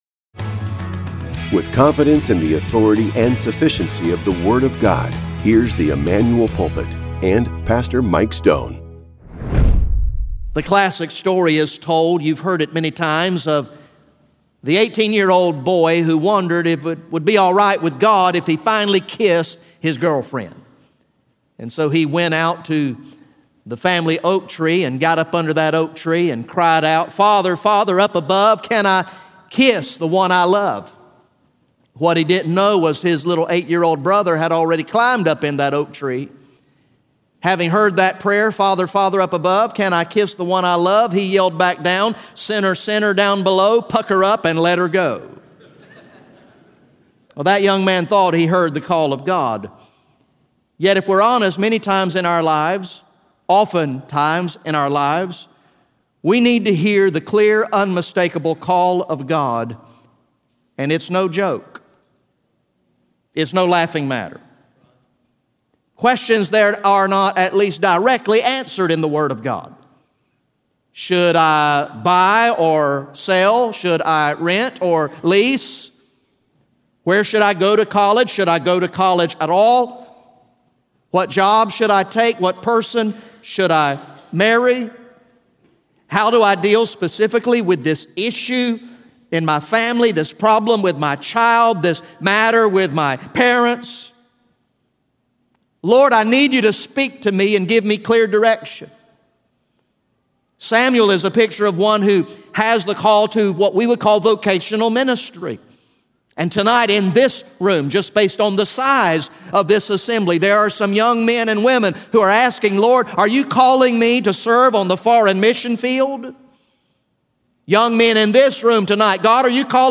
GA Message #06 from the sermon series entitled “Long Live the King!